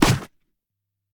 PixelPerfectionCE/assets/minecraft/sounds/item/shield/block1.ogg at ca8d4aeecf25d6a4cc299228cb4a1ef6ff41196e